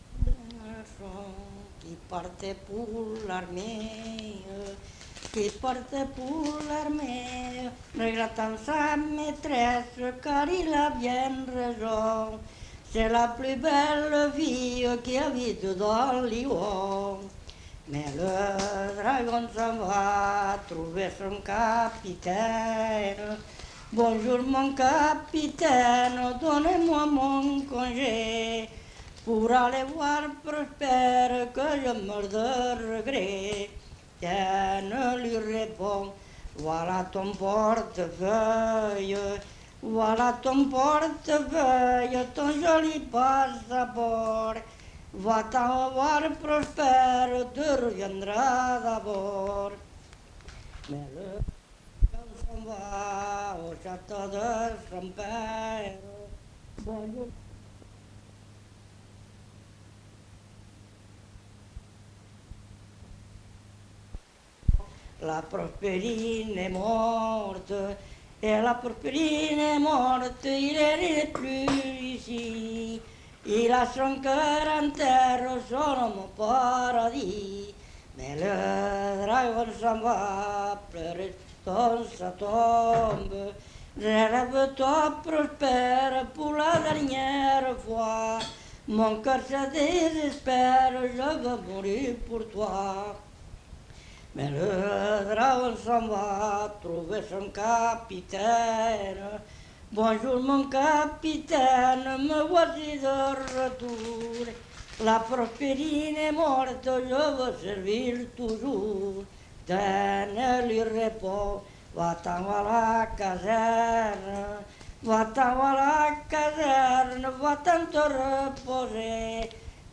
Genre : chant
Effectif : 1
Type de voix : voix d'homme
Production du son : chanté